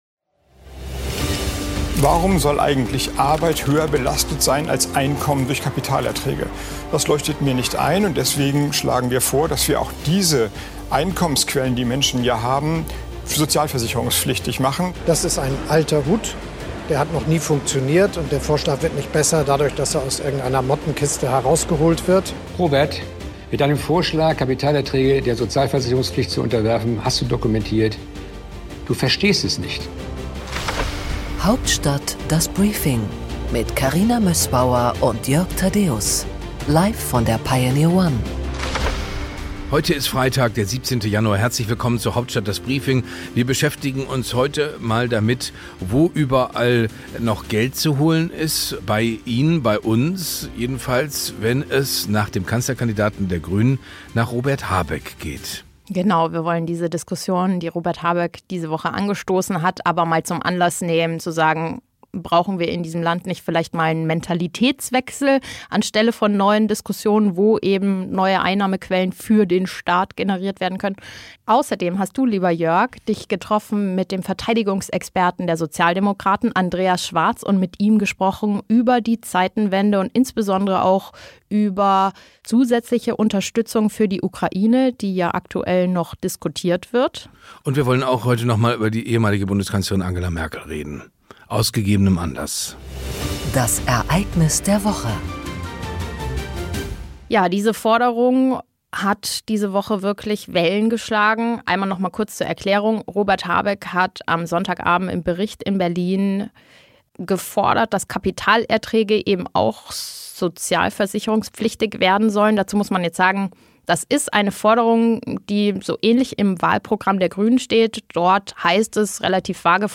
Im Interview der Woche: Andreas Schwarz (SPD), Mitglied im “Gremium Sondervermögen Bundeswehr”, spricht mit Jörg Thadeusz über das 3 Mrd. Euro Hilfspaket für die Ukraine, für dessen Verabschiedung im Deutschen Bundestag die Stimmen der Union und FDP benötigt werden.